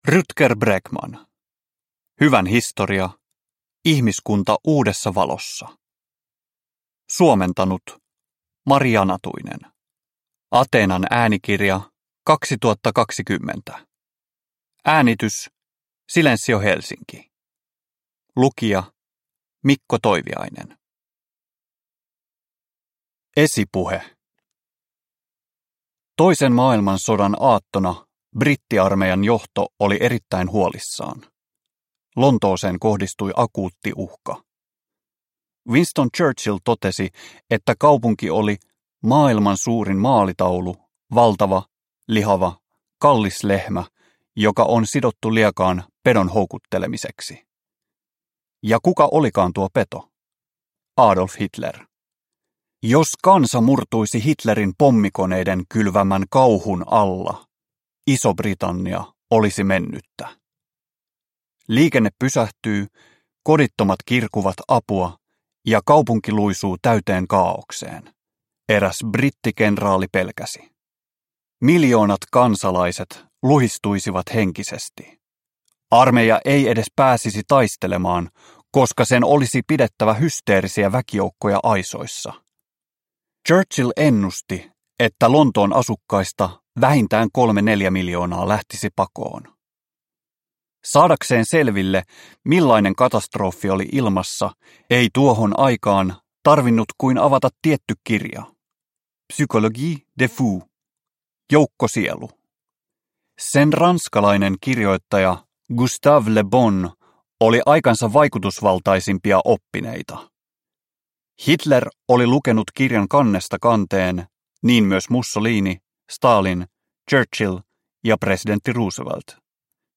Hyvän historia – Ljudbok – Laddas ner